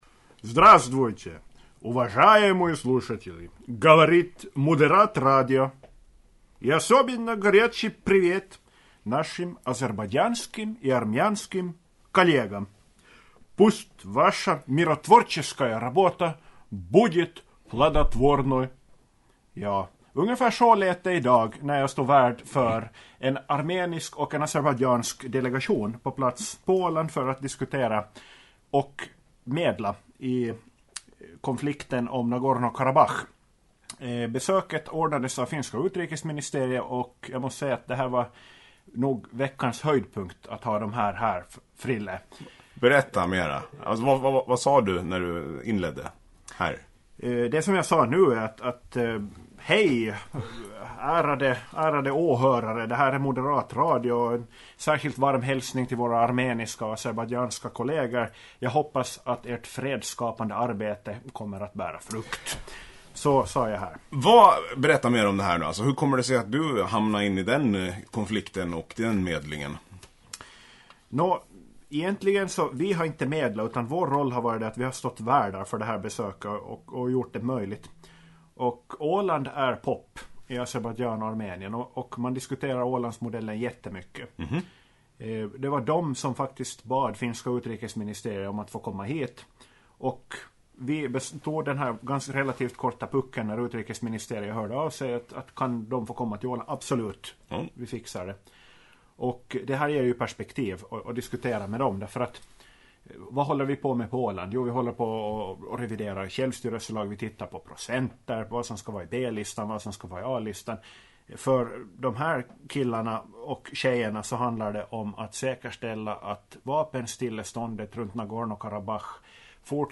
Dagens podd börjar med en hälsning på ryska, fortsätter med en diskussion om oppositionens spörsmål, företagsbesök, fiskeriprogram och centralbaltic kontaktpunkt. Idag var tyvärr partiordförande Ehn upptagen så kansliminister Valve och undertecknad fick hålla låda.